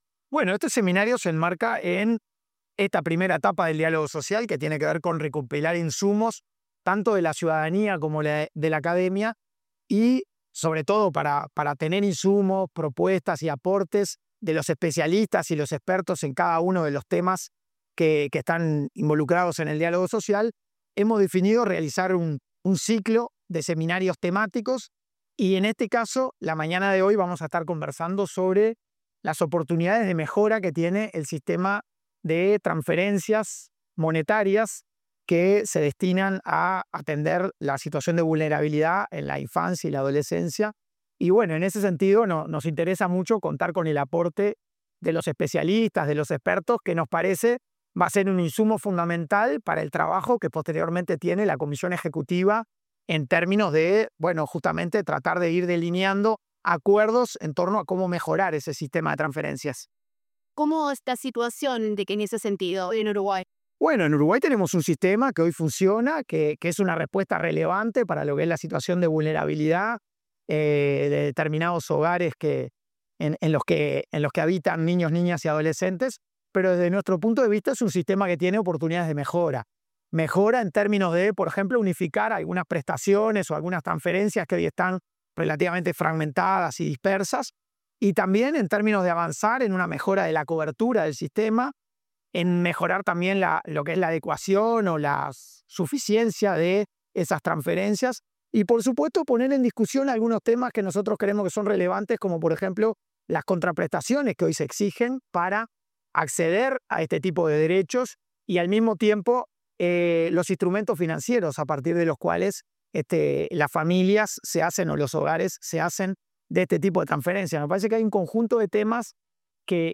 Declaraciones del coordinador del Diálogo Social, Hugo Bai | Presidencia Uruguay
El coordinador del Diálogo Social, Hugo Bai, realizó declaraciones en el marco del seminario Evidencia para Repensar las Trasferencias Monetarias